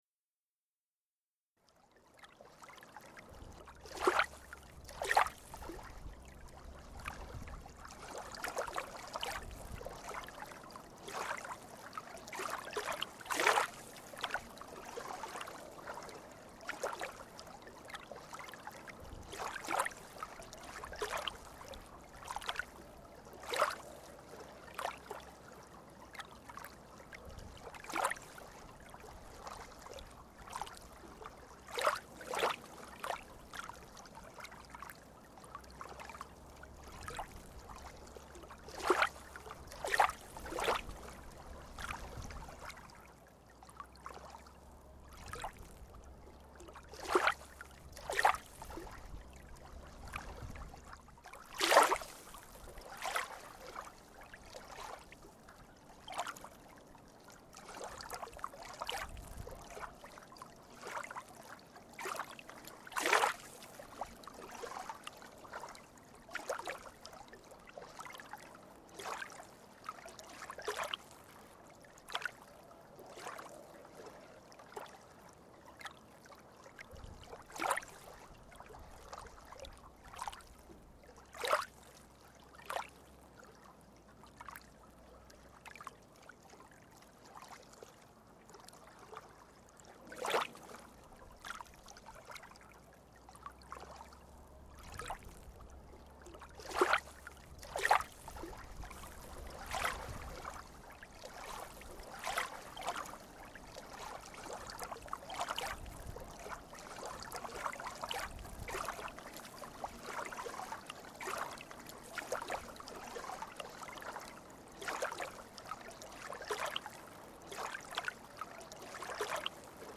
Aufnahme in einem Hotelpool- Foto: TELOS • Leises Plätschern im Pool.
Wasser-leises-Plätschern-im-Pool-WEB.mp3